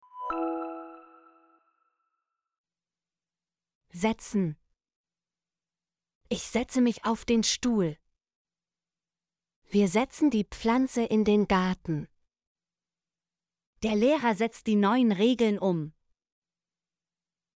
setzen - Example Sentences & Pronunciation, German Frequency List